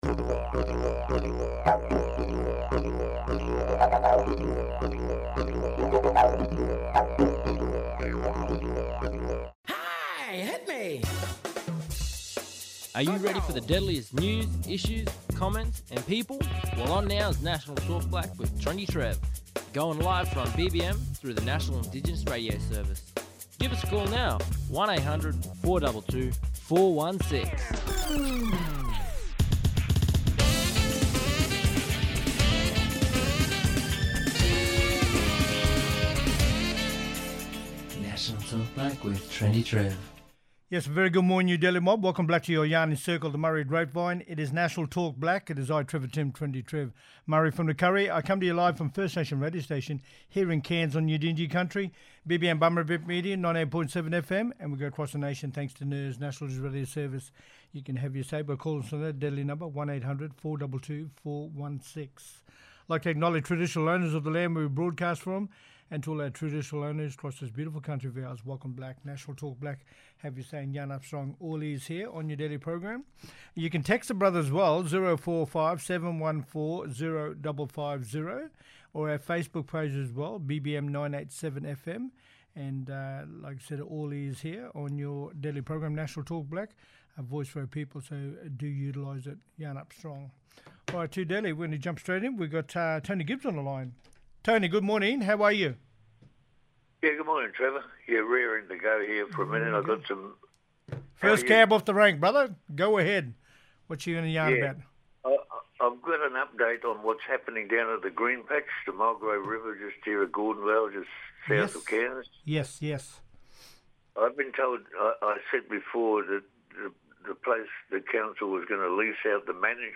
Tracey Ninyette, Commissioner for Children and Young People WA, talking about the Thousands of WA students to share their views as Speaking Out Survey begins in Broome. The Commissioner for Children and Young People’s team is hitting the road this week to deliver the Speaking Out Survey (SOS) to thousands of students across Western Australia.